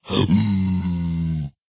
zpigangry4.ogg